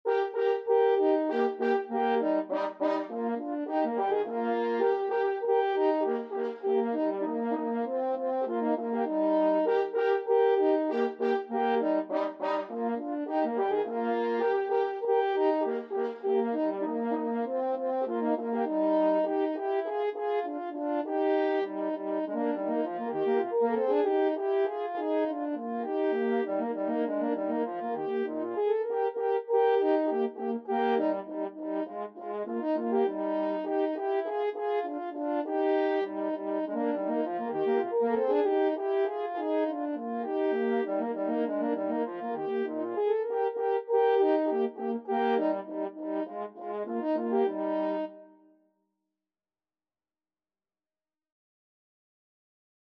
Allegro Molto (View more music marked Allegro)
2/4 (View more 2/4 Music)
French Horn Duet  (View more Easy French Horn Duet Music)
Classical (View more Classical French Horn Duet Music)